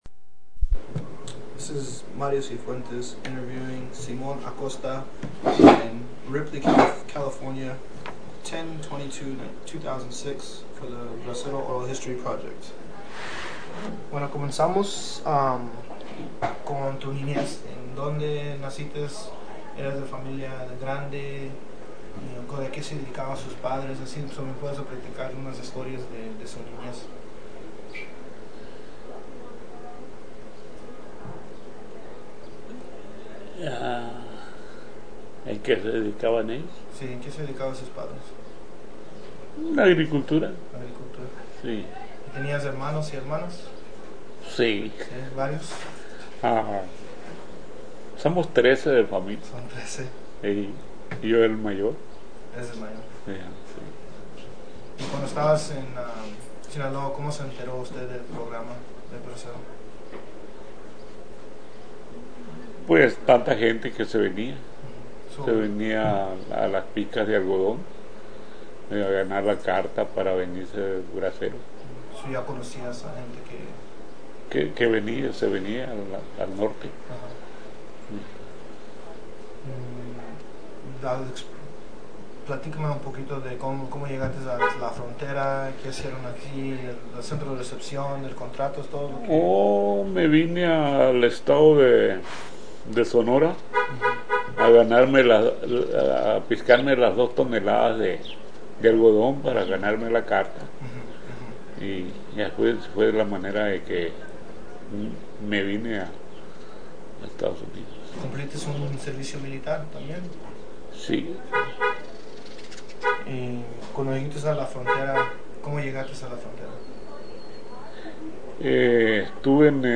Summary of Interview
Location Blythe, CA